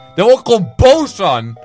Play Boos - SoundBoardGuy
boos-van.mp3